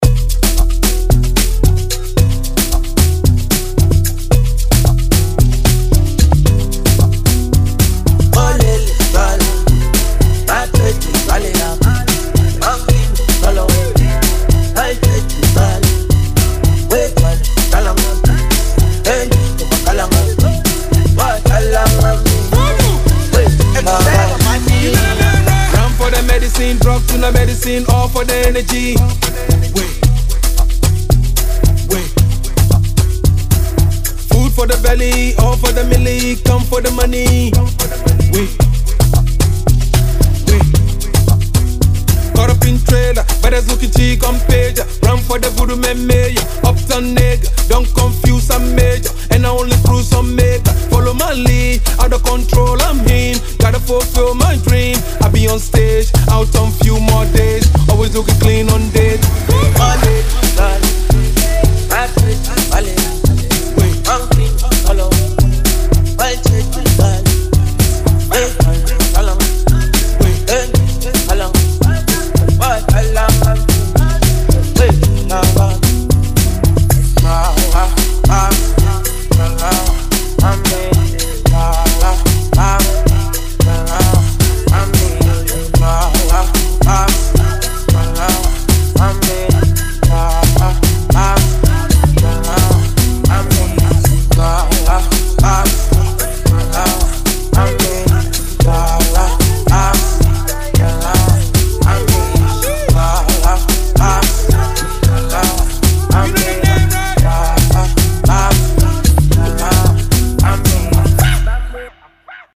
refix